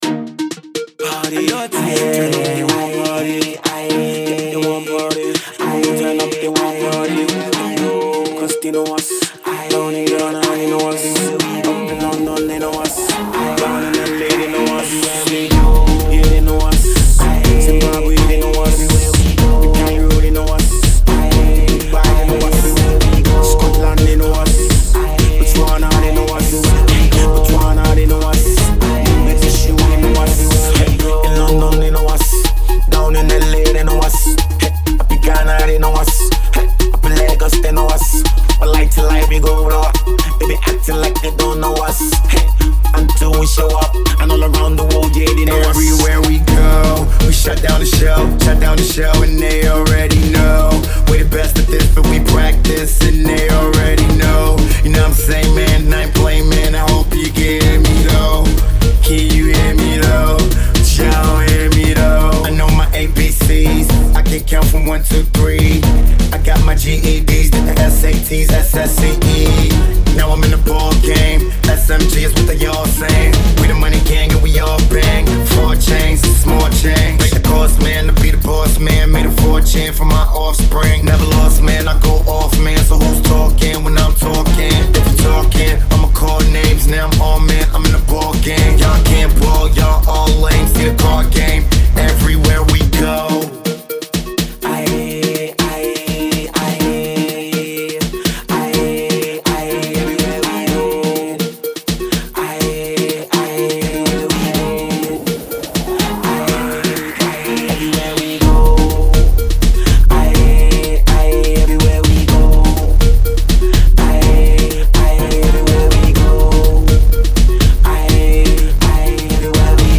electronic style of Afro Pop